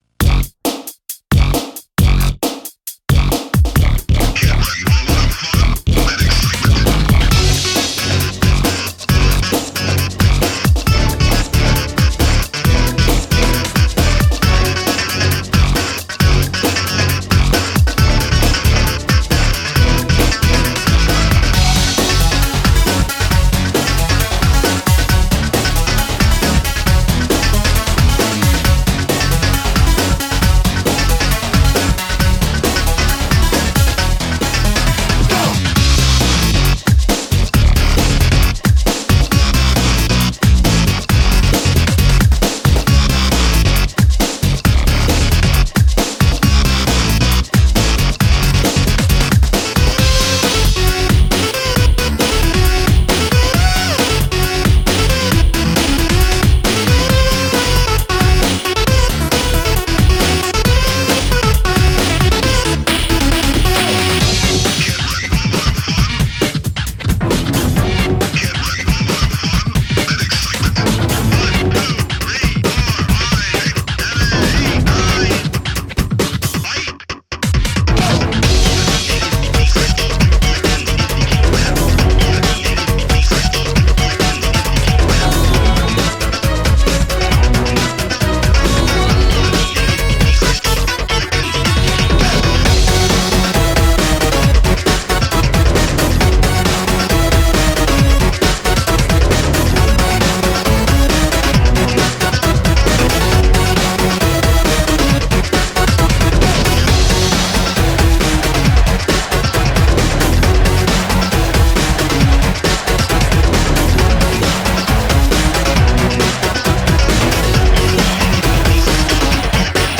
BPM135
Audio QualityPerfect (High Quality)
this PERFECT techno song will blast your body to its limits